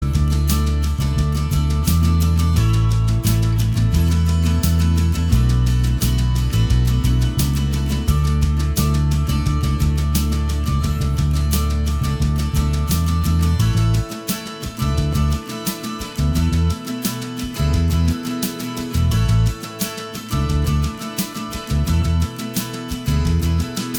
Minus All Guitars Pop (1990s) 3:35 Buy £1.50